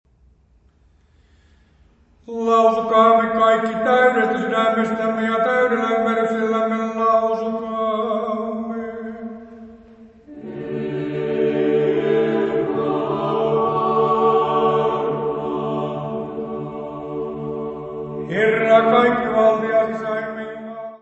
: stereo; 12 cm
Music Category/Genre:  Classical Music